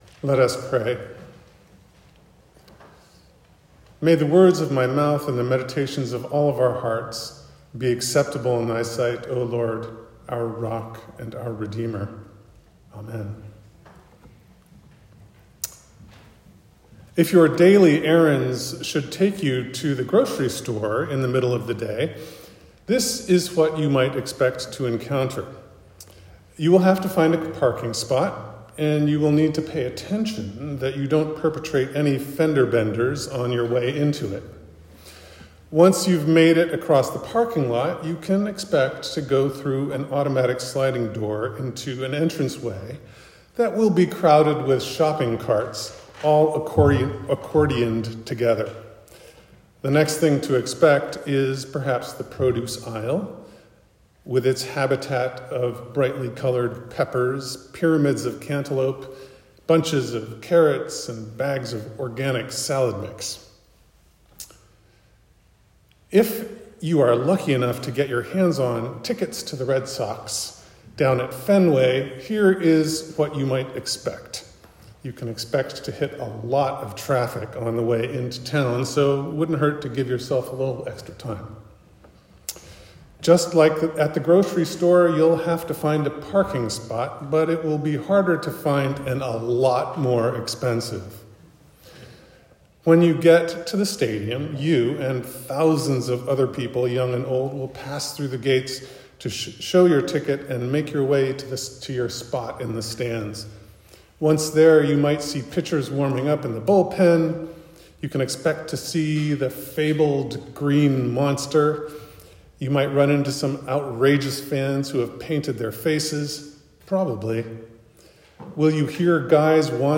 God the Unexpected – An Easter Sermon